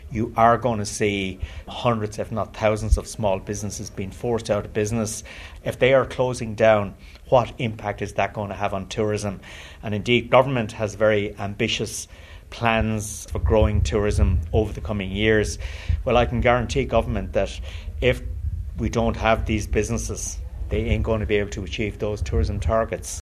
Economist Jim Power, author of the report, says many businesses that rely on tourism are facing an uncertain future: